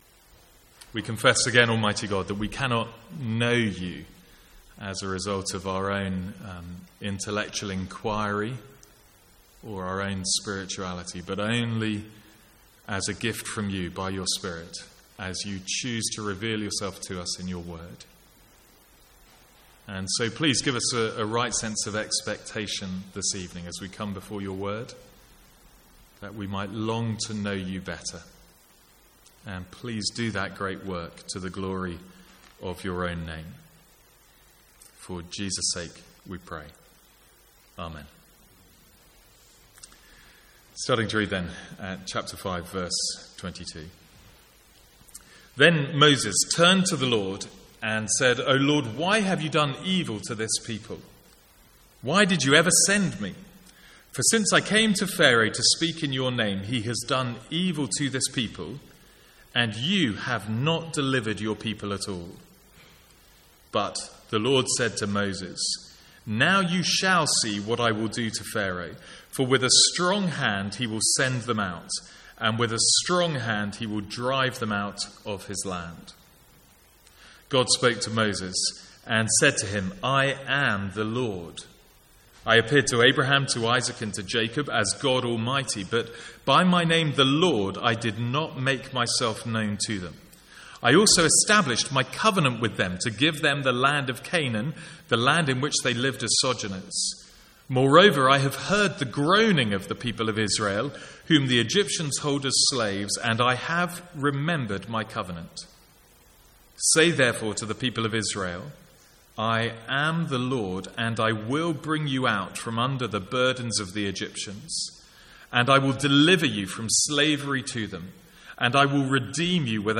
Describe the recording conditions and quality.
From the Sunday evening series in Exodus.